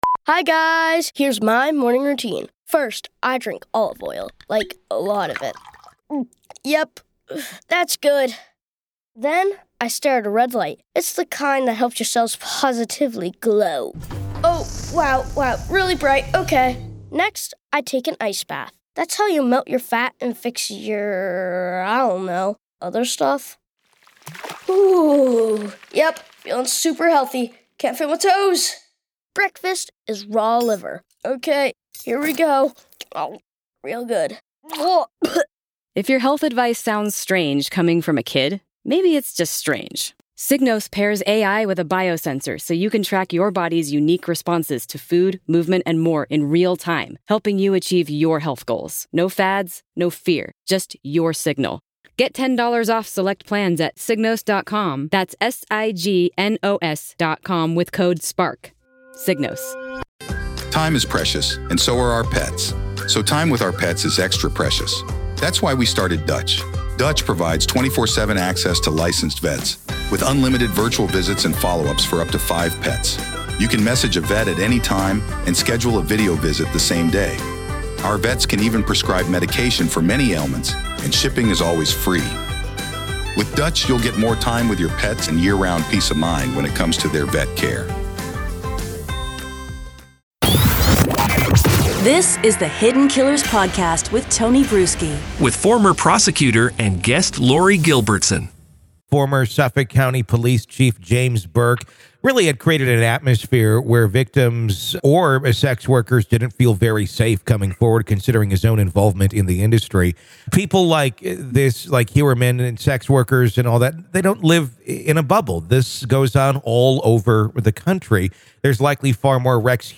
The conversation delved into the terrifying reality that there might be numerous Rex Heuermanns lurking around the nation, creating an environment where the police dismiss crimes reported by sex workers.